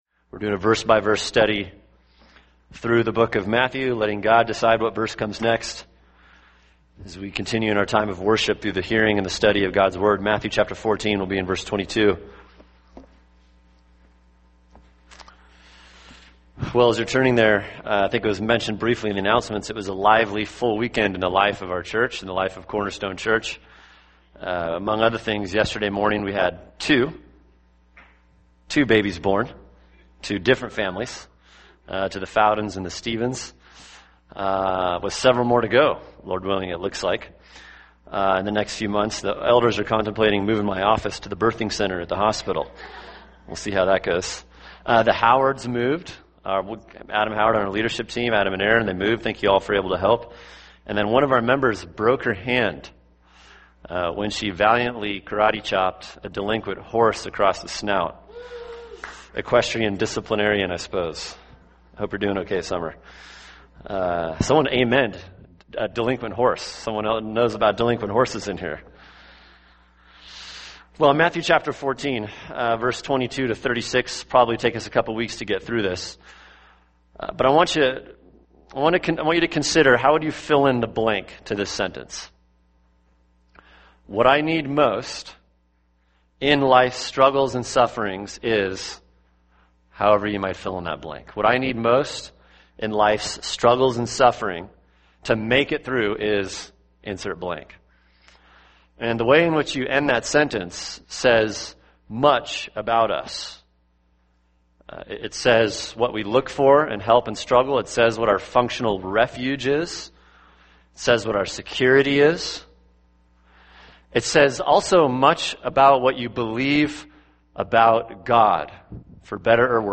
[sermon] Matthew 14:22-36 – The Lord of the Storm (part 1) | Cornerstone Church - Jackson Hole